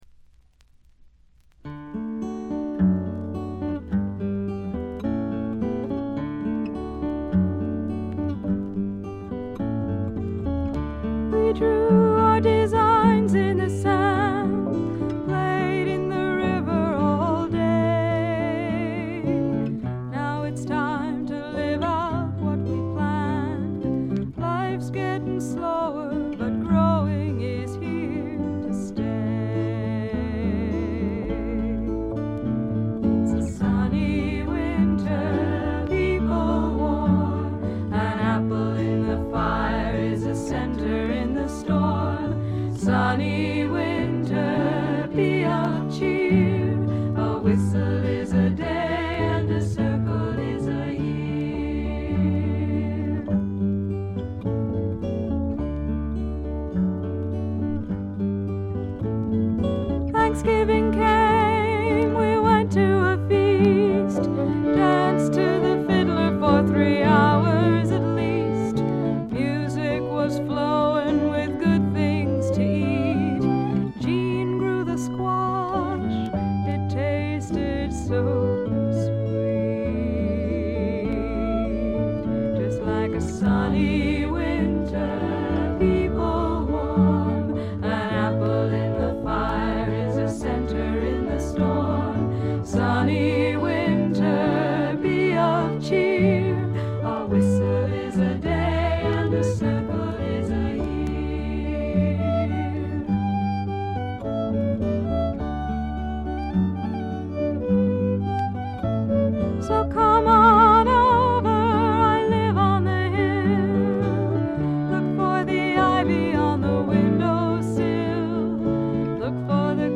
ホーム > レコード：米国 女性SSW / フォーク
弾き語りを中心にごくシンプルなバックが付く音作り、トラッドのアカペラも最高です。
Vocals, Guitar, Autoharp, Recorder